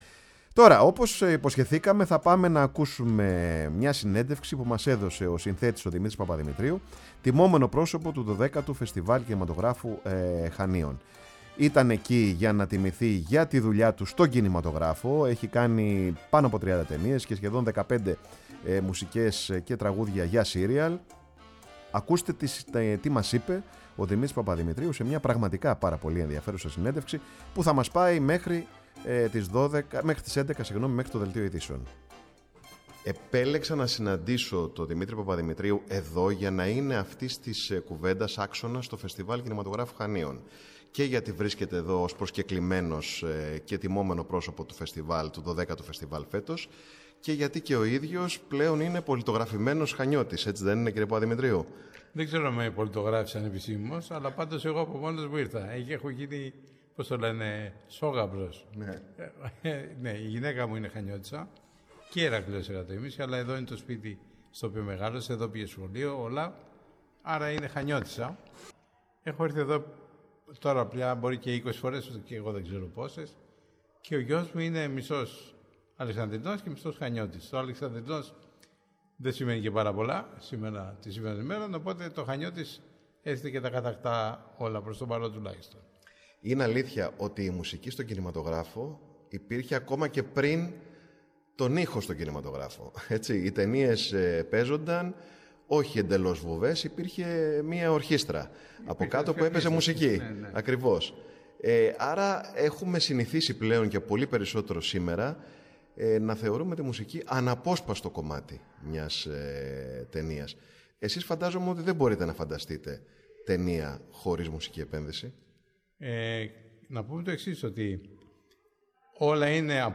στο 12ο Φεστιβάλ Κινηματογράφου Χανίων.
ΣΥΝΕΝΤΕΥΞΕΙΣ